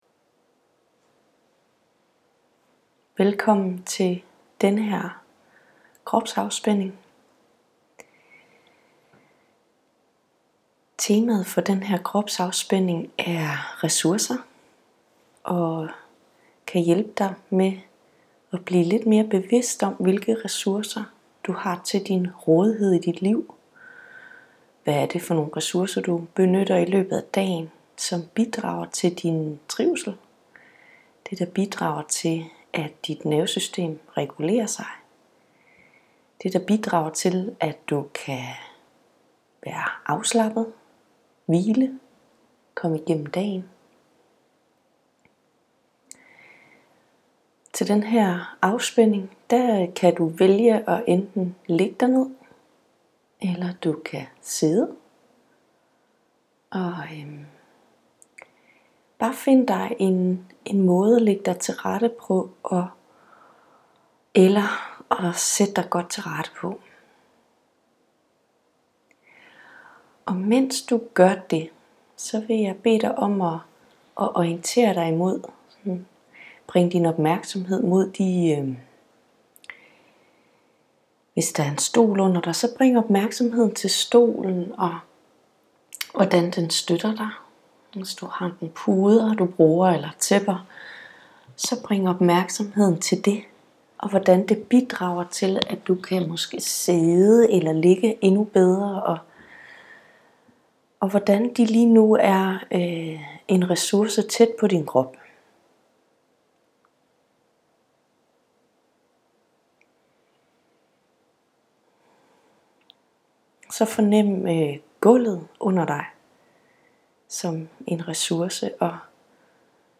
-- 20 MINUTTERS GUIDET ØVELSE MOD STRESS --
Her guider jeg dig i en 20 minutters øvelse til at komme tilstede i kroppen og i kontakt med alle dine ressourcer, omkring dig og i dig.